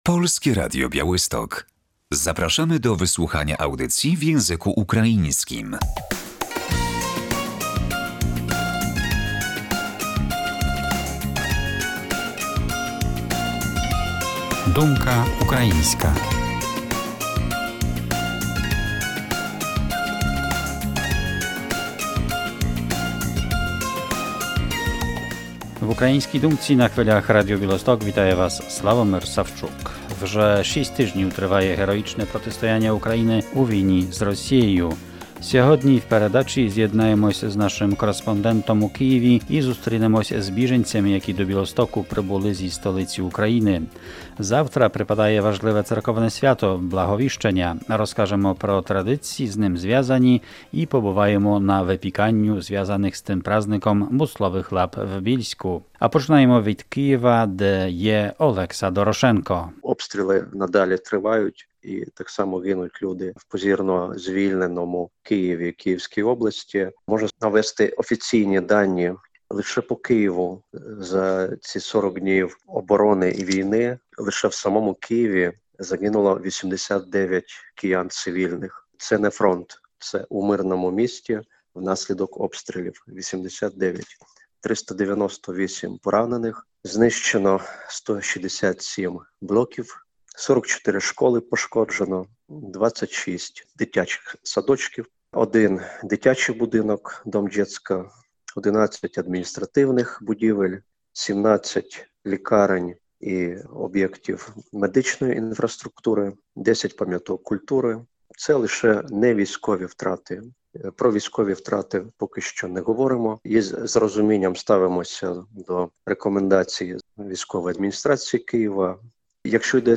Jutro obchodzimy Zwiastowanie – Błahowiszczenia. W audycji opowiemy o zwyczajach związanych z tym dniem i będziemy na wypiekaniu „busłowych łap” w Przedszkolu „Leśna Polana” w Bielsku Podlaskim